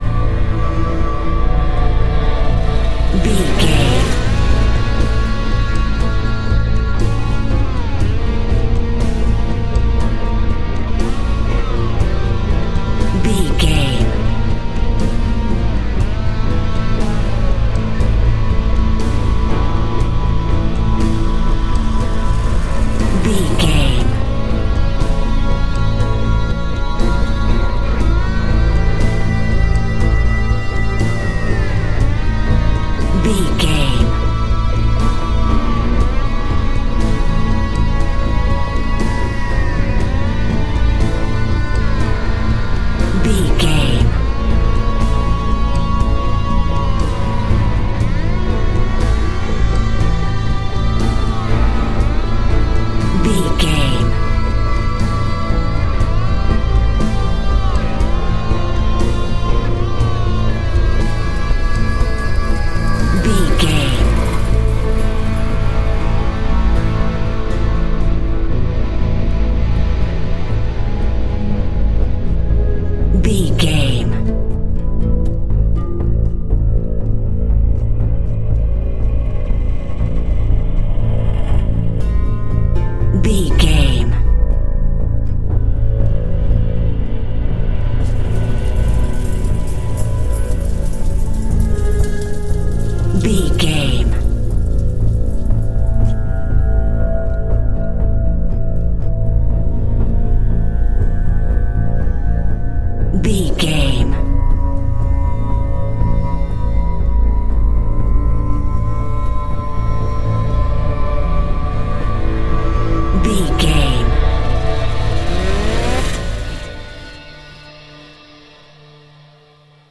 Thriller
Aeolian/Minor
Slow
piano
synthesiser
electric guitar